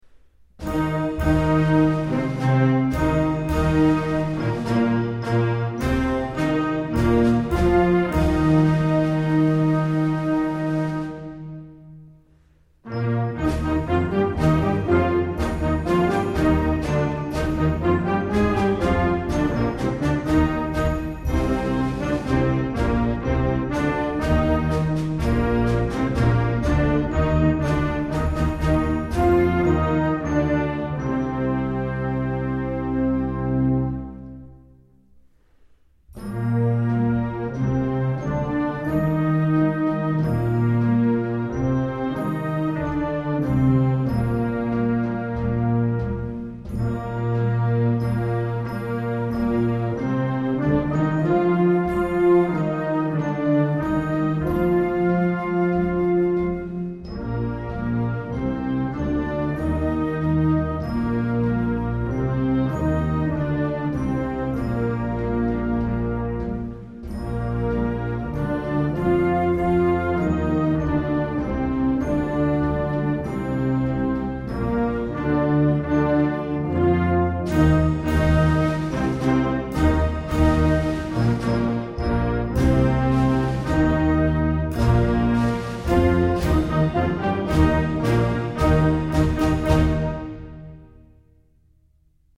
Genre: Band
Flute
Oboe
Bassoon
Clarinet in Bb
Alto Saxophone
Trumpet in Bb
Horn in F
Trombone
Tuba
Percussion (Snare Drum, Bass Drum)
Auxiliary Percussion (Suspended Cymbal, Triangle)
Piano